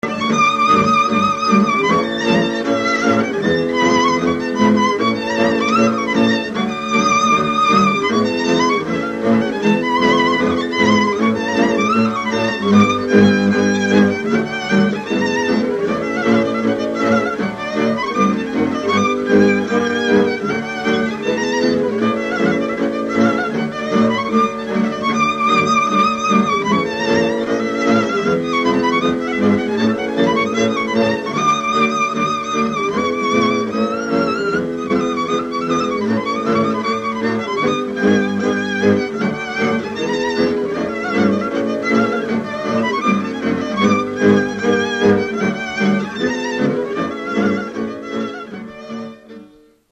Dallampélda: Hangszeres felvétel
hegedű
brácsa Műfaj: Csárdás Gyűjtő